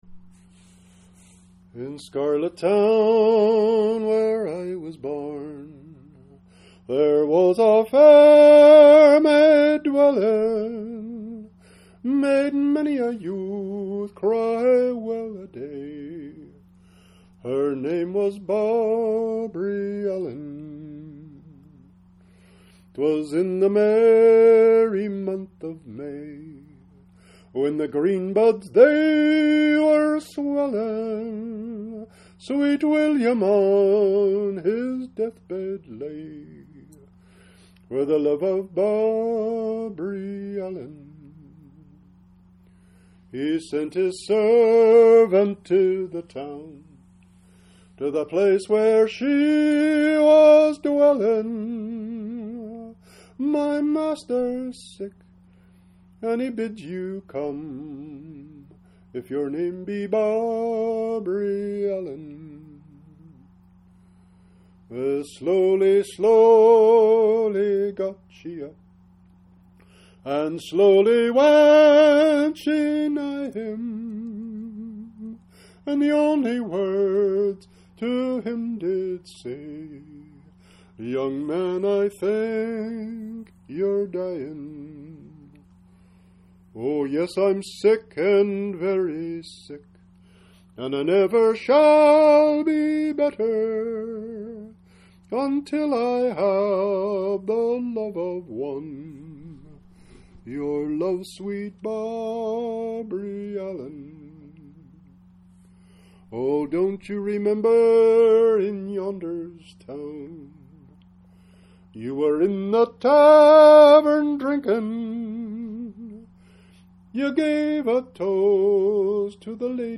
PDF Files: ballad